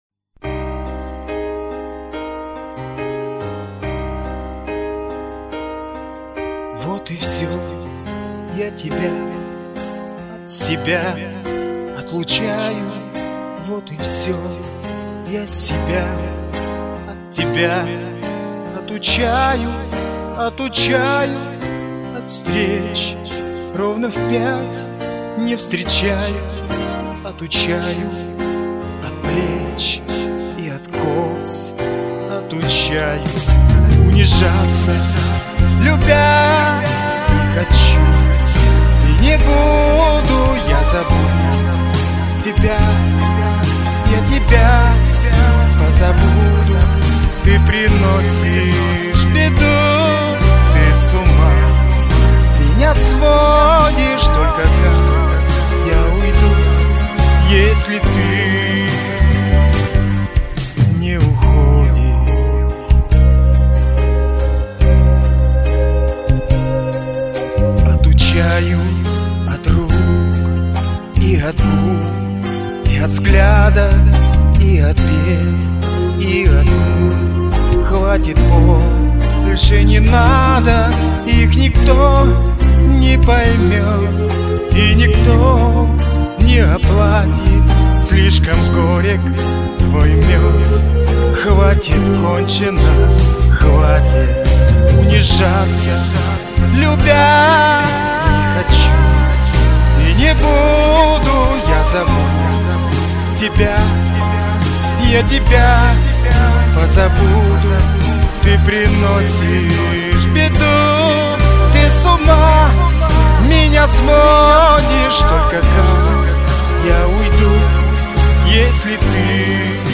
Ну, слегка съехал в тональности, но в целом не плохо даже3
Здесь на слух, даже непрофессионалу, непопадание в ноты! 2:1